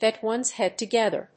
アクセントgèt one's héad togéther